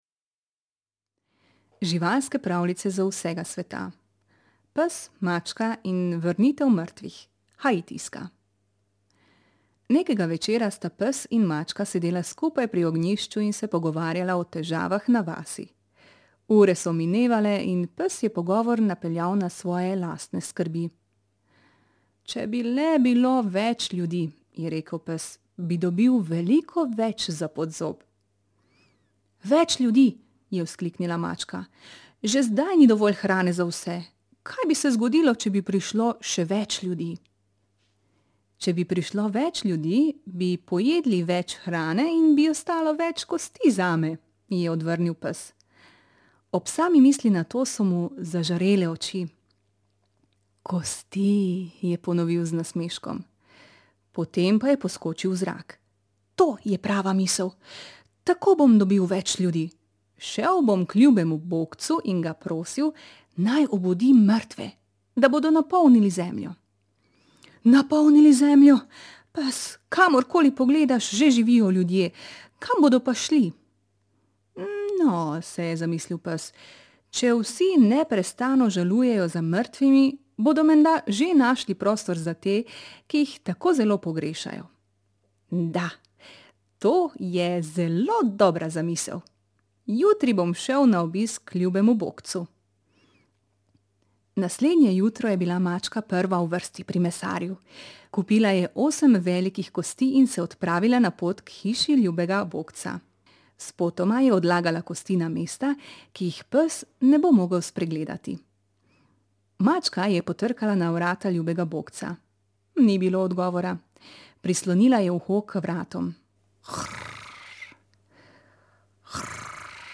Zvočne pravljice